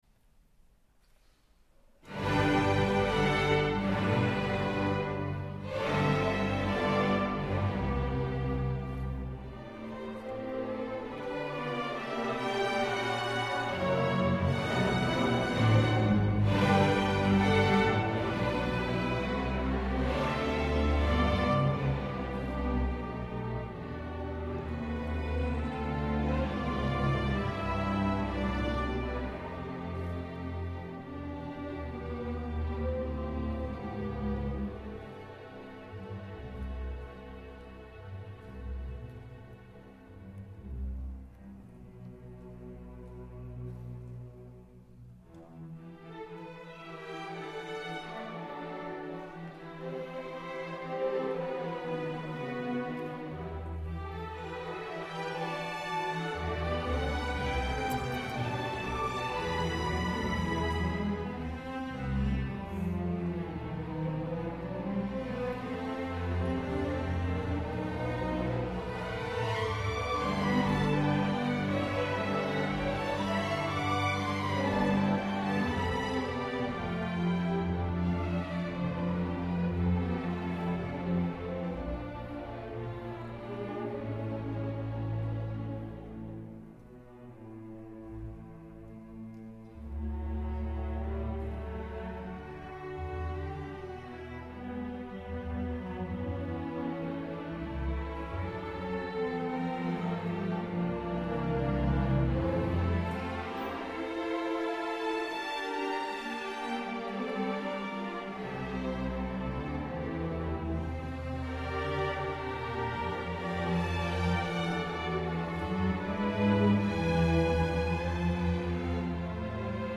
作曲　啼鵬（ていほう）
クラシック音楽の深さとポピュラー音楽の親しみやすさとおしゃれな和音が爽やかに結びつき、富谷高校生の若々しさ・素直さ・富谷高校の校舎の雰囲気・富谷市のキレイな街並みまで想起させるような、まさに「Little symphony of Tomiya」の名にふさわしい曲になっています。
録音は、定期演奏会で世界初演した際のものです。